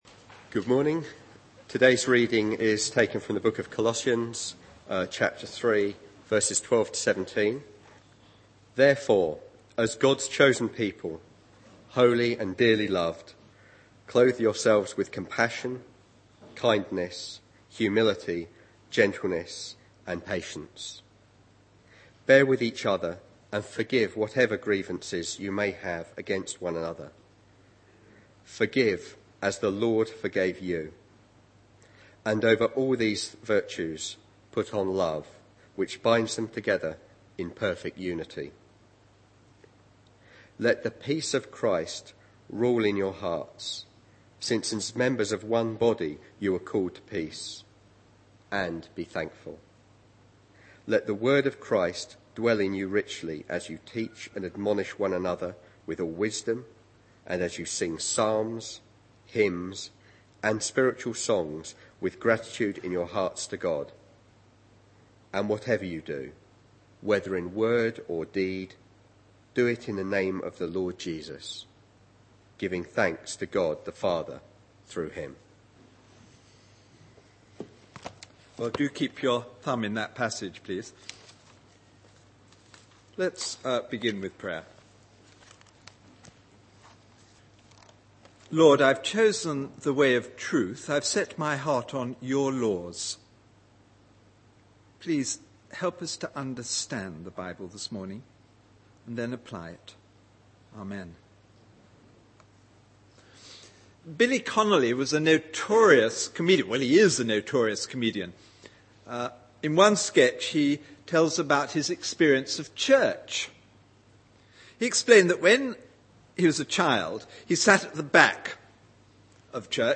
Series: Sunday Mornings